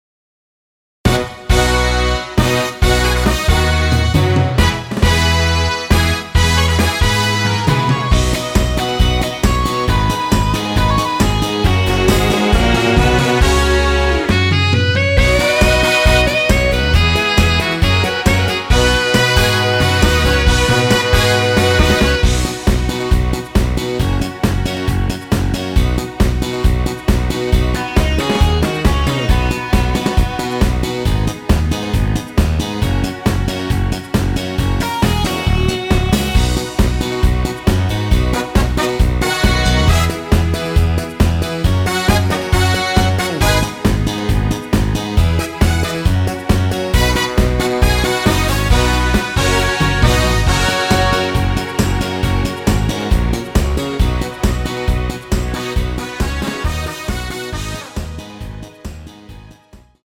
원키에서(+1)올린 MR입니다.
◈ 곡명 옆 (-1)은 반음 내림, (+1)은 반음 올림 입니다.
앞부분30초, 뒷부분30초씩 편집해서 올려 드리고 있습니다.
중간에 음이 끈어지고 다시 나오는 이유는